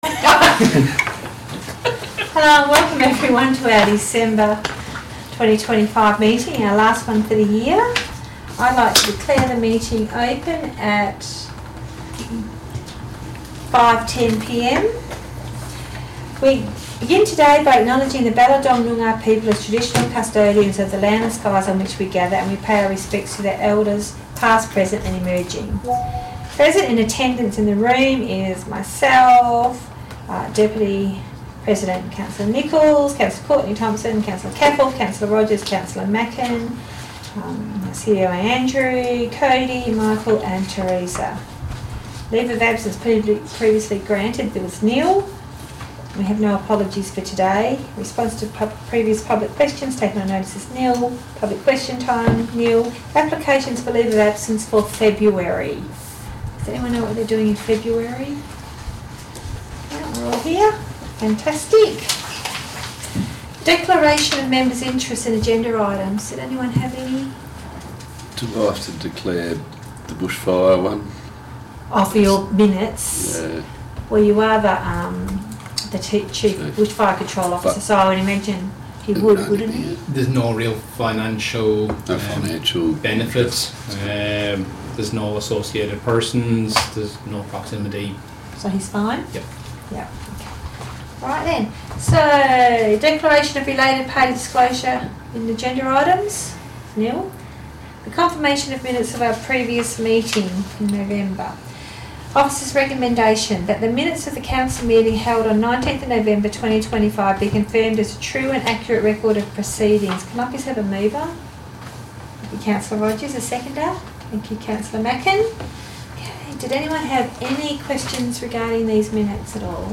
Ordinary Council Meeting - 10 December 2025 » Shire of Tammin